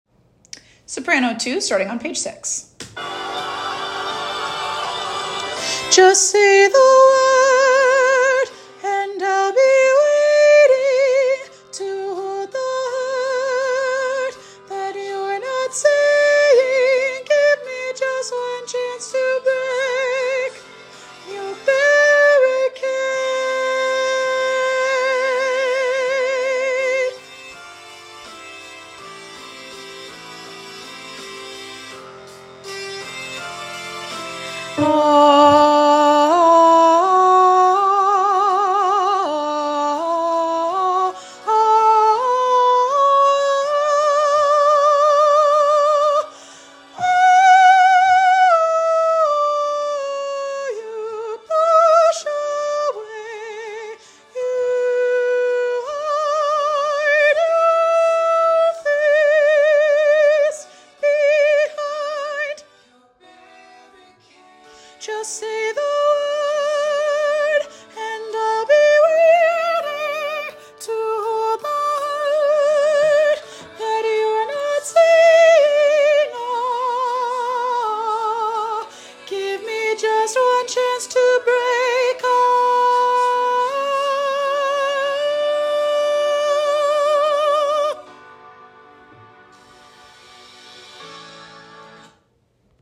with lush harmonies, tall chords, and an epic conclusion.
Sop 2